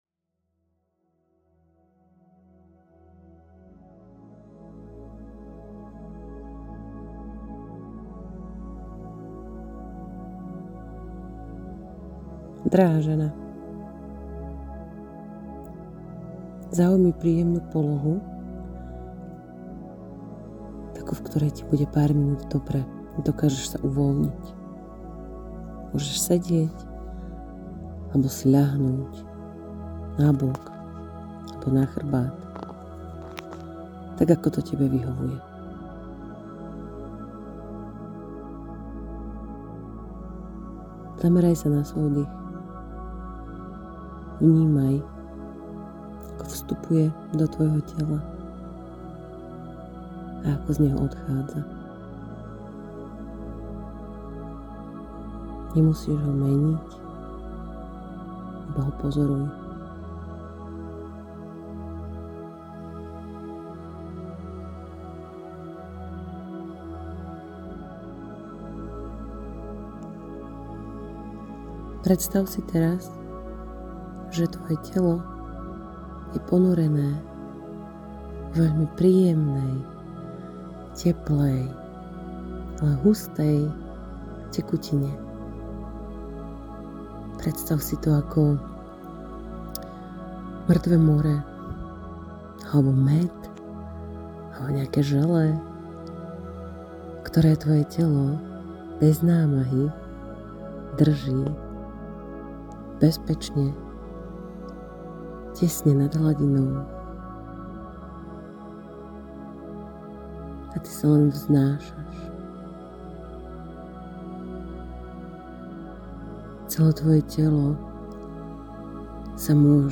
tehotenske-uvolnenie.mp3